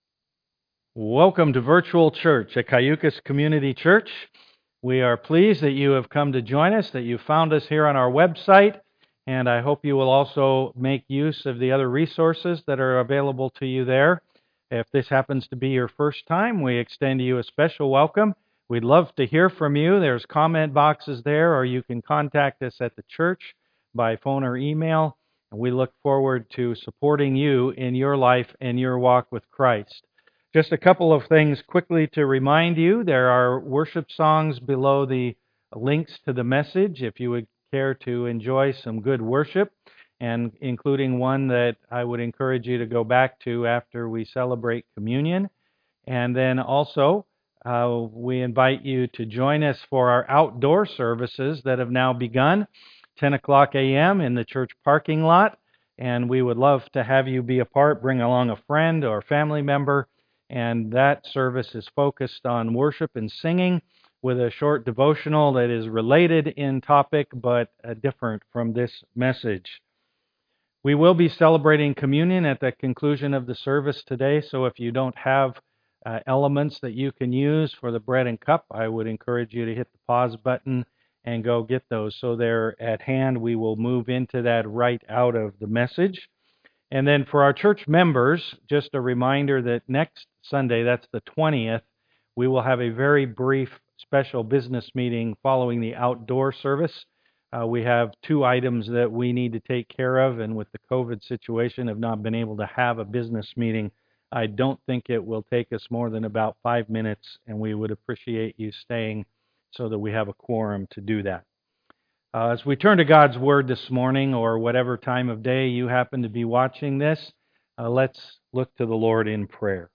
Passage: Exodus 11-12 Service Type: am worship